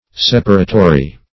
Separatory \Sep"a*ra*to*ry\, a.